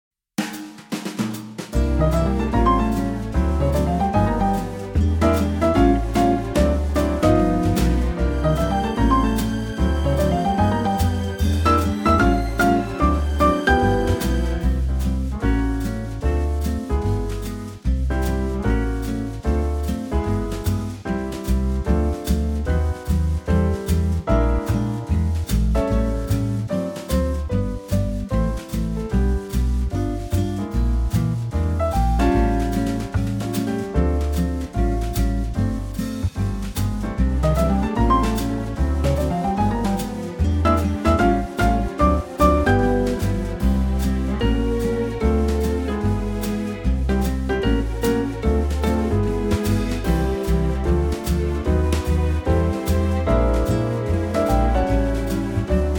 key C 3:45
key - C - vocal range - B to E